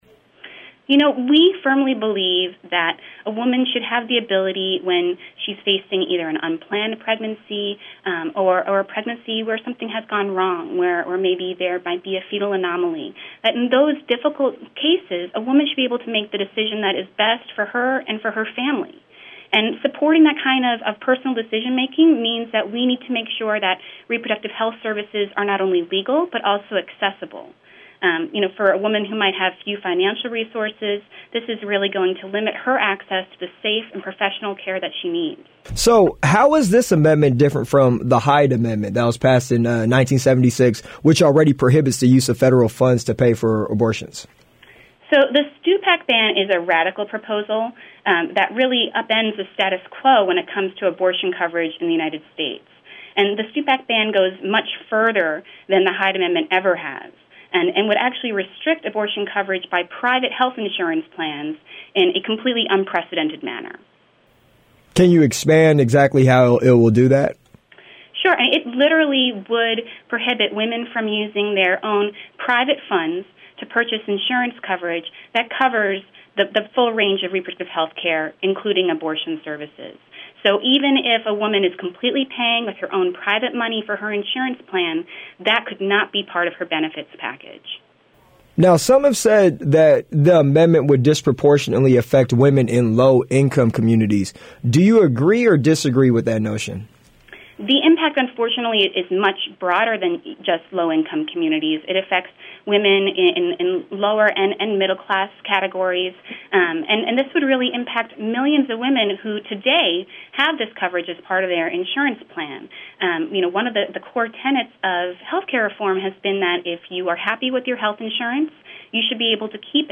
abortionhealthinterview.mp3